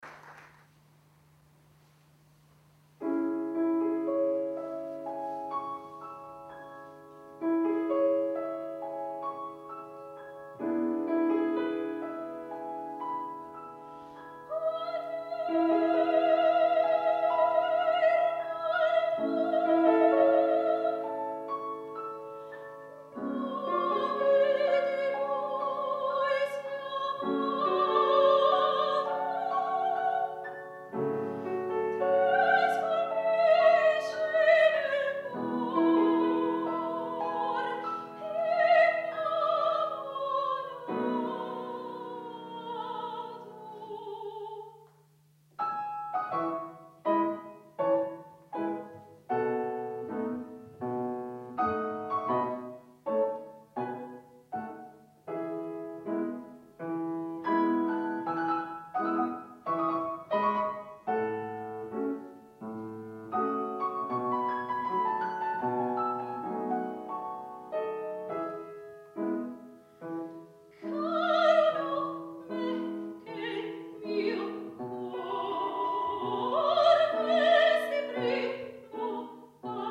17 ottobre 2009 - II OTTOBRE MUSICALE A PALAZZO VALPERGA - Concerto - Arie Italiane